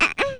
mariopaint_baby.wav